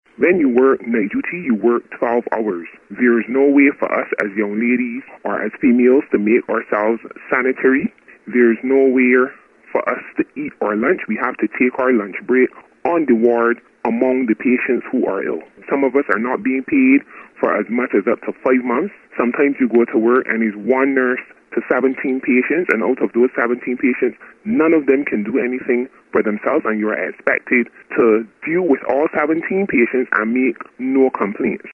One of the striking nurses contacted Starcom Network News to outline a list of grievances.